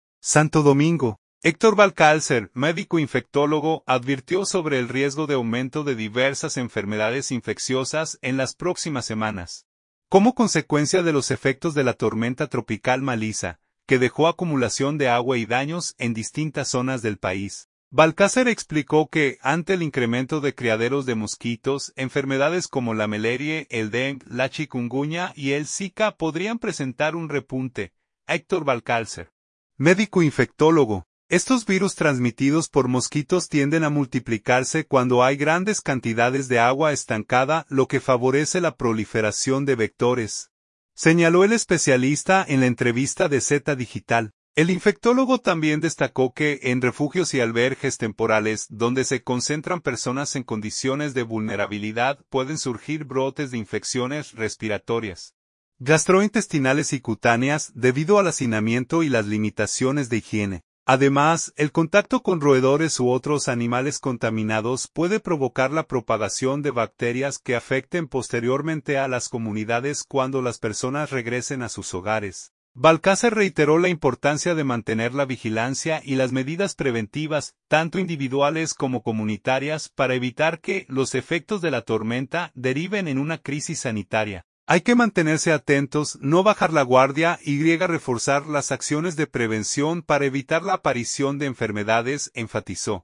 “Estos virus transmitidos por mosquitos tienden a multiplicarse cuando hay grandes cantidades de agua estancada, lo que favorece la proliferación de vectores”, señaló el especialista en la entrevista de Z Digital.